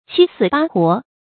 七死八活 注音： ㄑㄧ ㄙㄧˇ ㄅㄚ ㄏㄨㄛˊ 讀音讀法： 意思解釋： 如同多次死去活來一般。形容受盡痛苦。